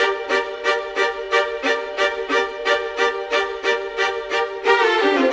Rock-Pop 07 Violins 02.wav